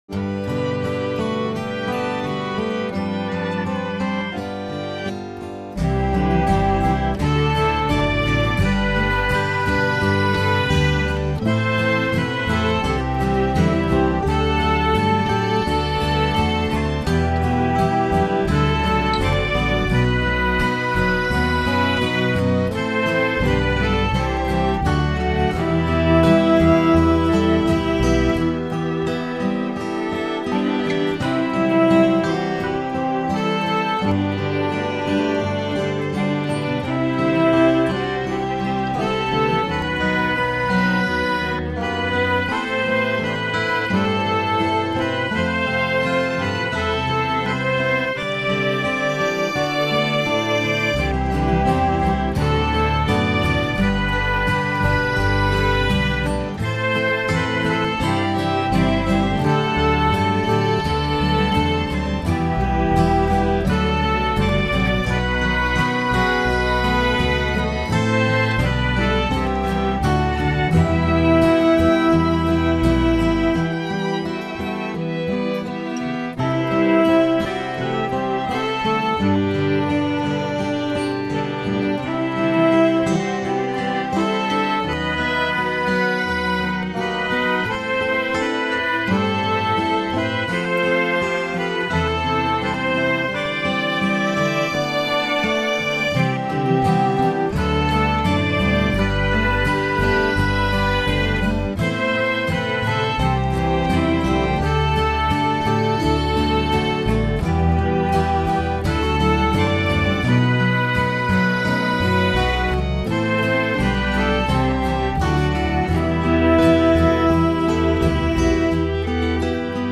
communion song